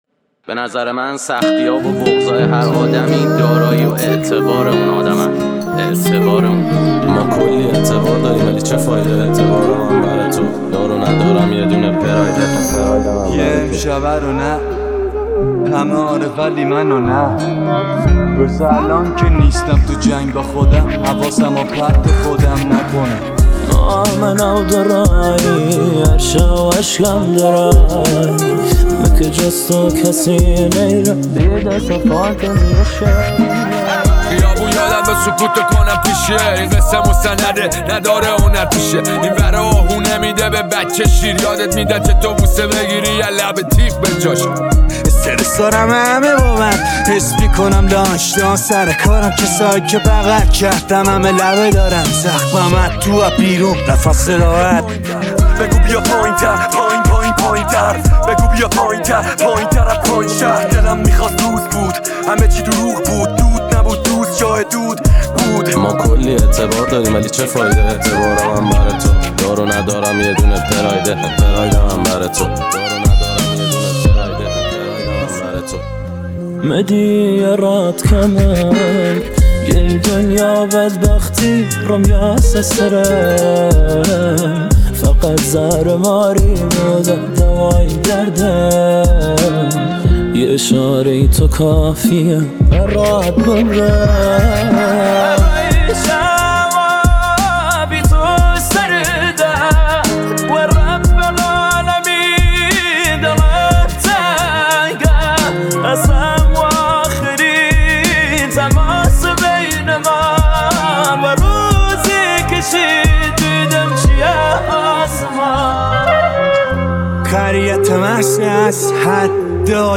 ریمیکس جدید رپ